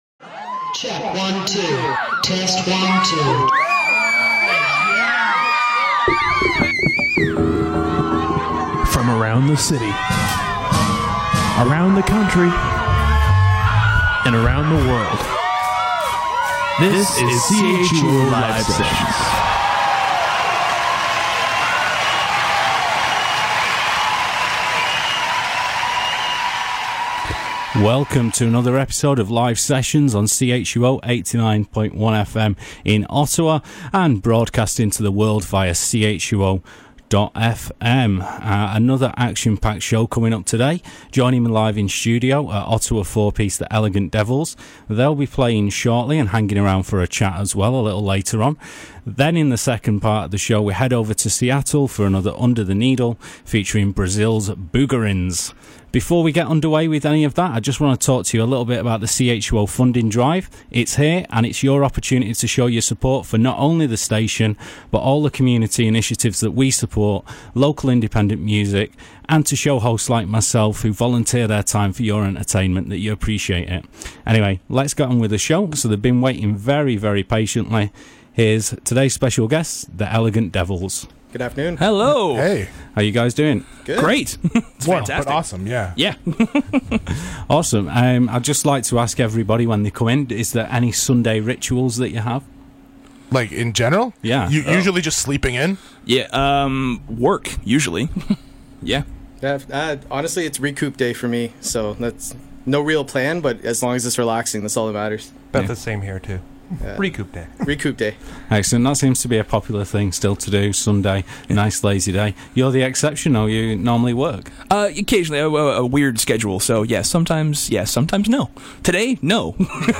CHUO 89.1 Interview.mp3